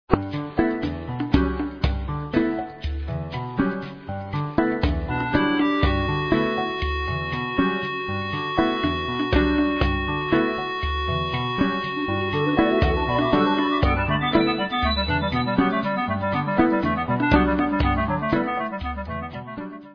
West-african backing adds world music flavour "special"
sledovat novinky v oddělení Jazz/Fusion